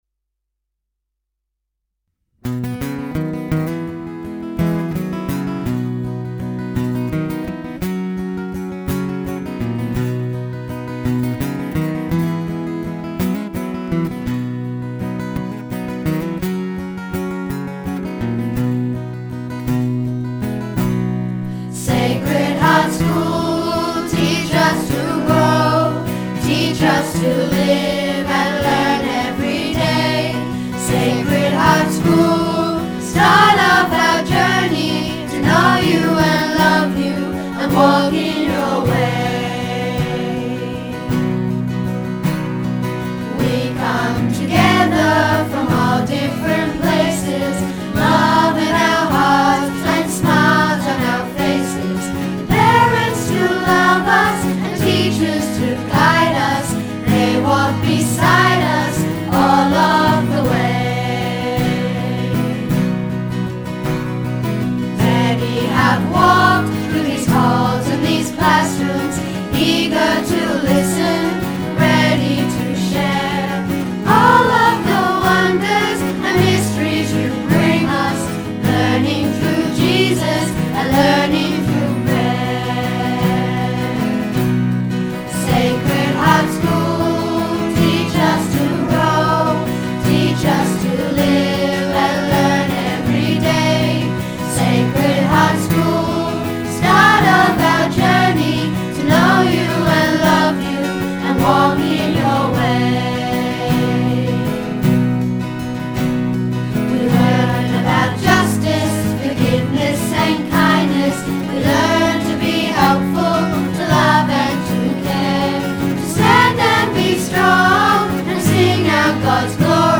School Song